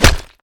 打中木盔甲zth070510.wav
通用动作/01人物/03武术动作类/打中木盔甲zth070510.wav